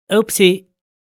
Oopsie Sound Effect
Experience the Oopsie sound effect, a funny voice clip perfect for memes and viral content.
Genres: Sound Effects
Oopsie-sound-effect.mp3